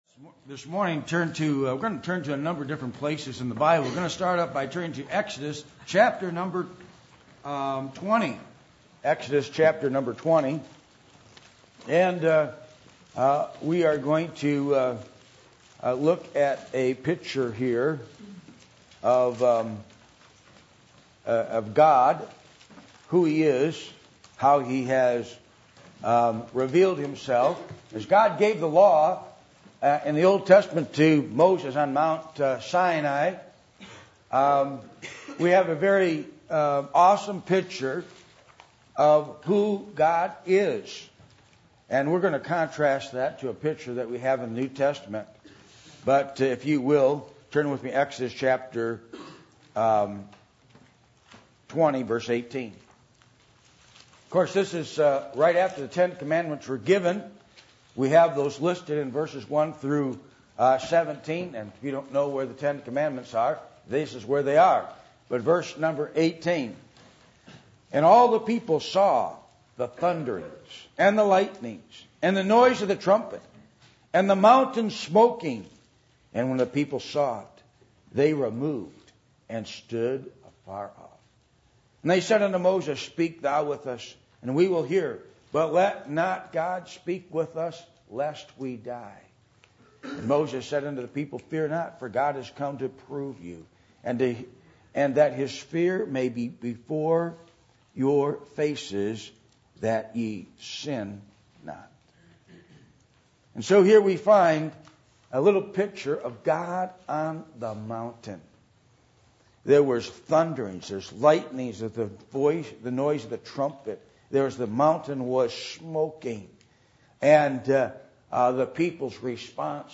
1 John 1:2-3 Service Type: Sunday Morning %todo_render% « The Godly Seed